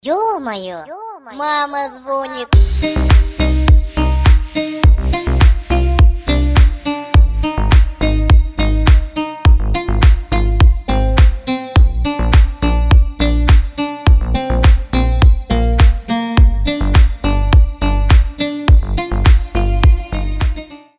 Рингтоны ремиксы
Mashup , Deep house